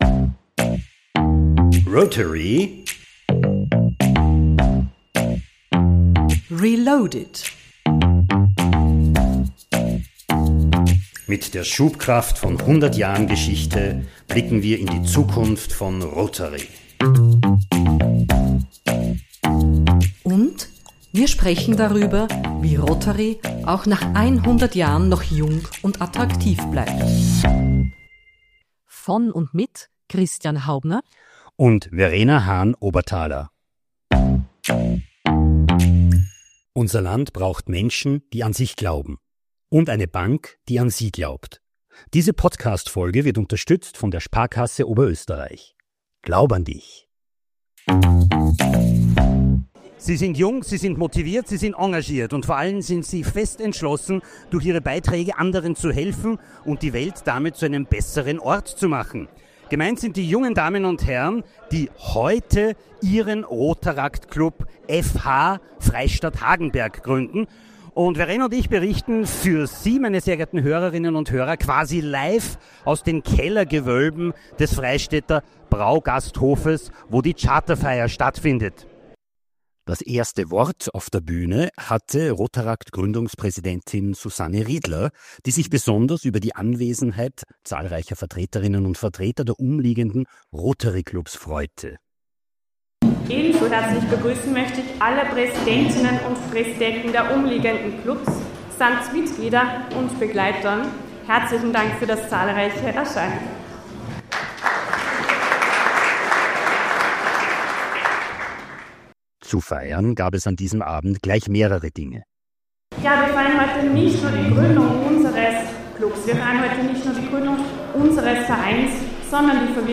In dieser Folge können Sie bei einer Geburt dabei sein, nämlich bei der Geburt des Rotaract-Clubs Freistadt-Hagenberg. Zu Wort kommen neue Mitglieder und „Geburtshelfer“.